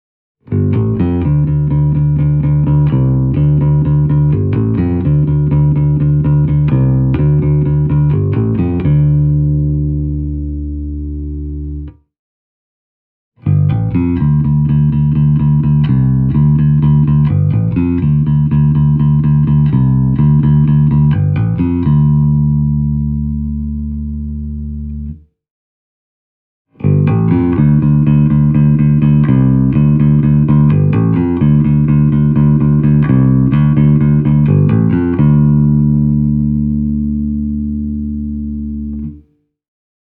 In the rehearsal studio – where I also recorded the audio clips for this review – the Micro-CL left me in no doubt that it would be fully up to its task at rehearsals and even smaller gigs.
With regard to its sound the tiny stack proved to be a thoroughbred Ampeg: The tones on offer are fat and juicy, with the raunchy midrange growl this company’s bass amps are famous for.
The audio clips have been recorded using my ’87 Rickenbacker 4003 (starting with the neck pickup):
Ampeg Micro-CL – fingerstyle